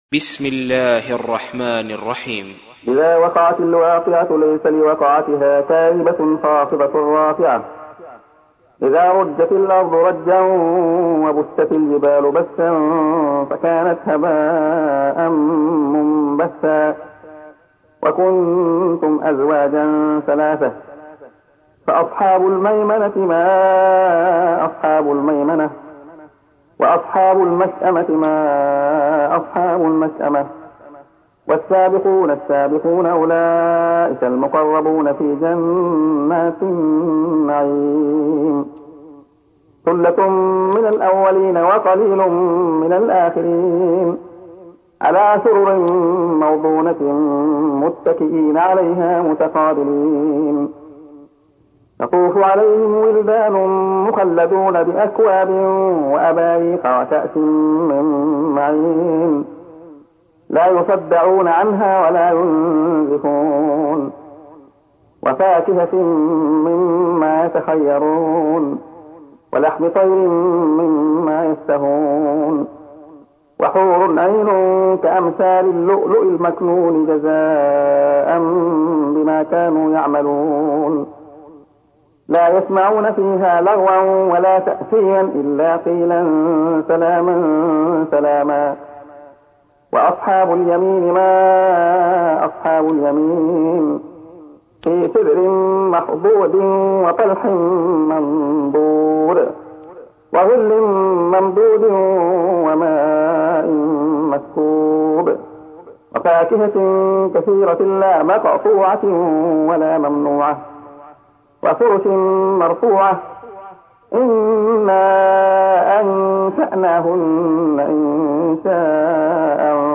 تحميل : 56. سورة الواقعة / القارئ عبد الله خياط / القرآن الكريم / موقع يا حسين